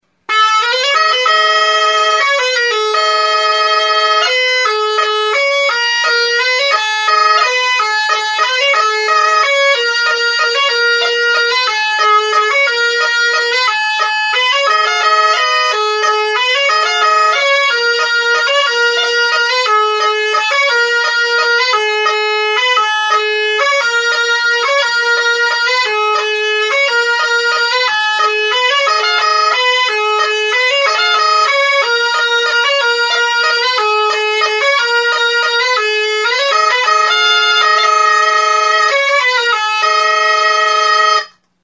Aerophones -> Reeds -> Single fixed (clarinet)
Recorded with this music instrument.
ALBOKA
Klarinete bikoitza da.